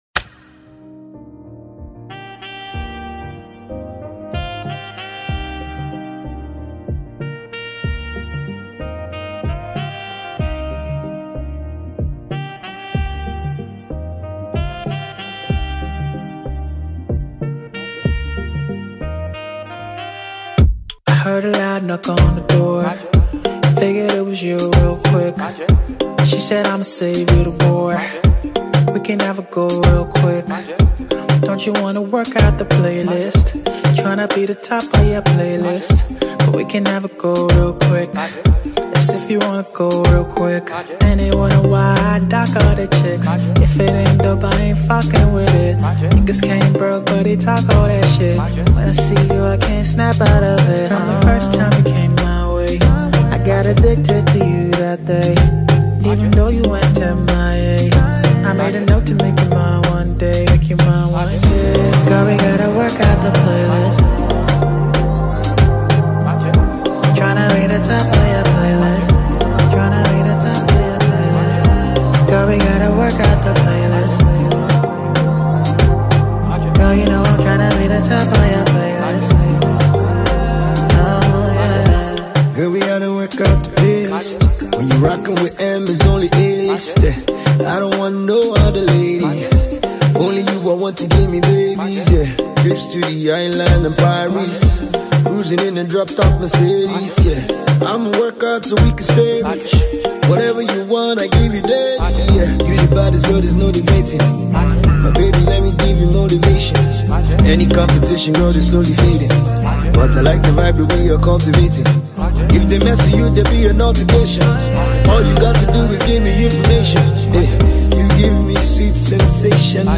Afro-fusion/R&B singer
boasts scintillating beats with a relaxing flow.
contagious rhythm